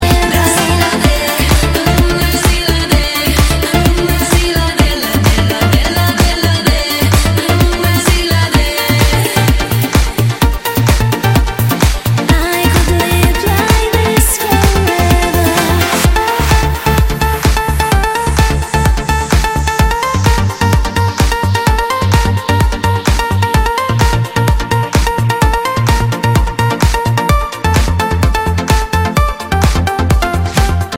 • Качество: 128, Stereo
Dance Pop